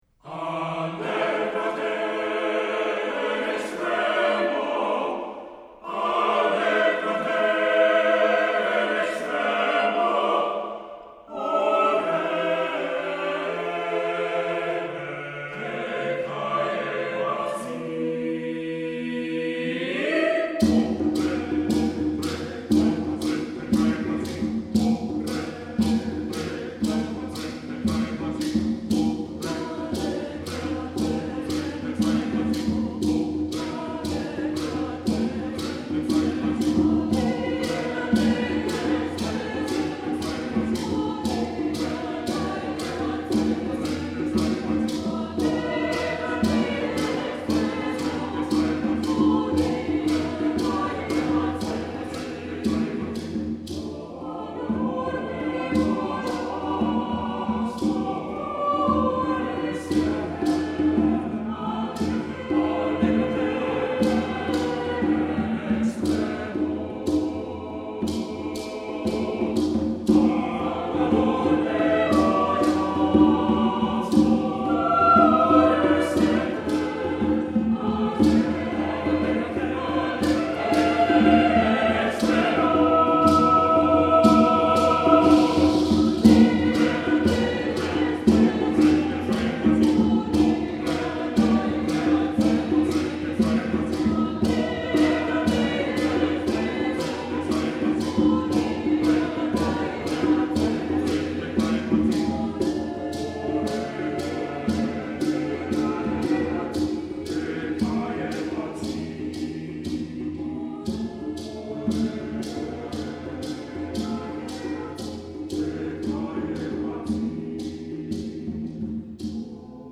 Accompaniment:      A Cappella
Music Category:      Choral
highly interesting and rhythmic setting